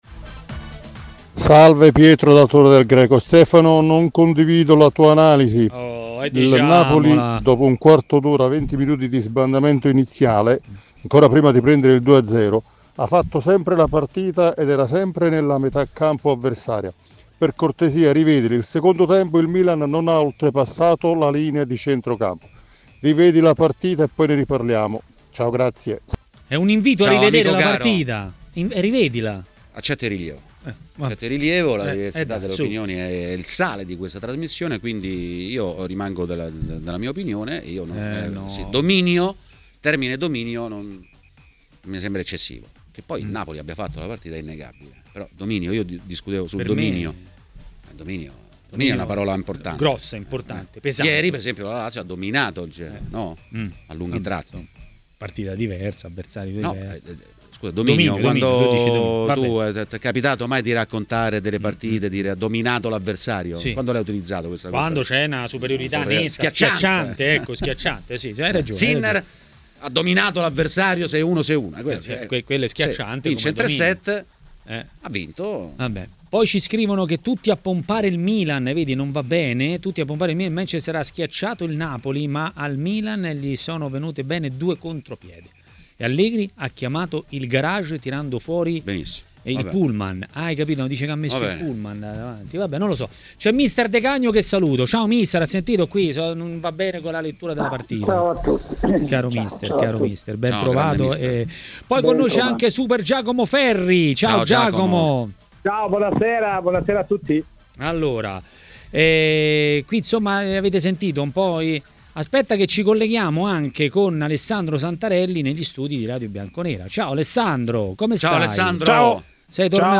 Mister Gigi De Canio è stato ospite di Maracanà, nel pomeriggio di TMW Radio.